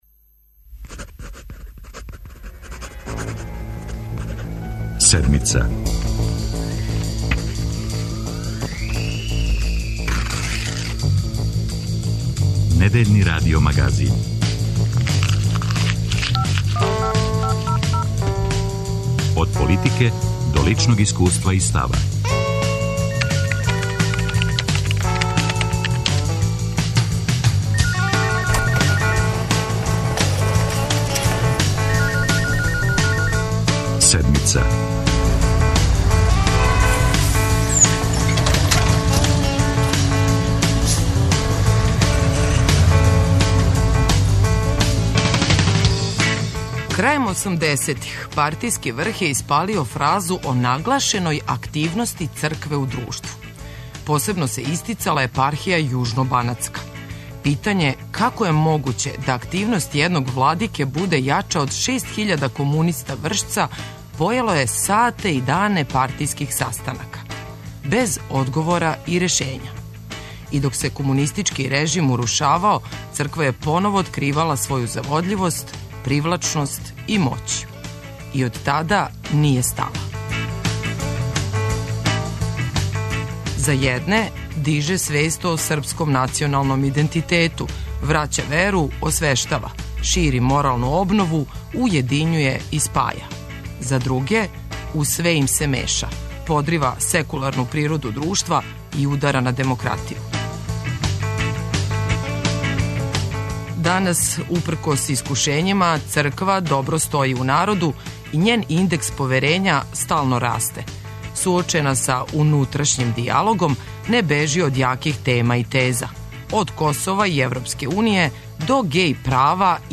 Српски патријарх Иринеј ексклузивно за Седмицу говори о искушењима народа, цркве и државе и данима пред нама.